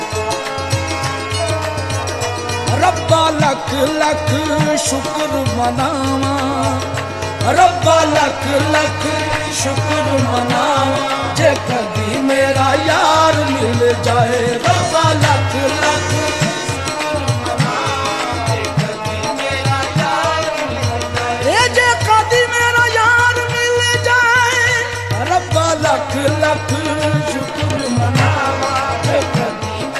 Beautiful green water 💦 River sound effects free download
Beautiful green water 💦 River meet with Indus river at N-35 Shahra Karakuram Road